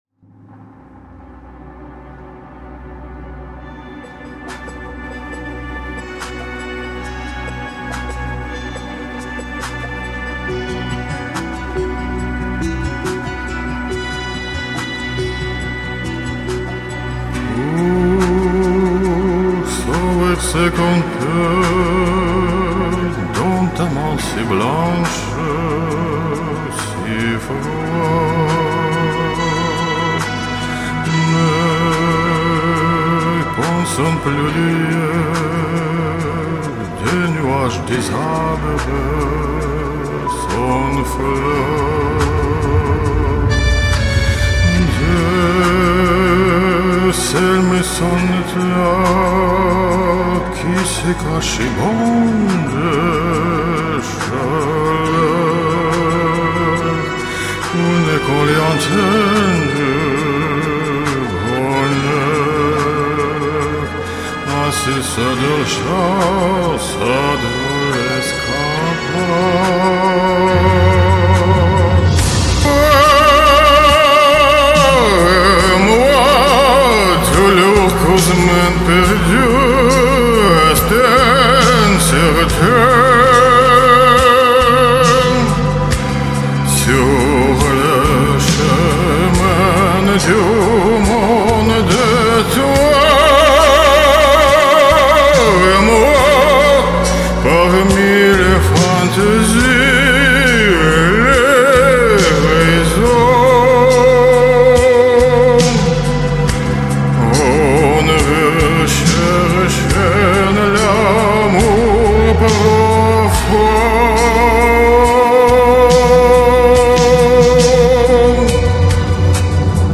Из-за этого звук слишком закрытый и глухой. 2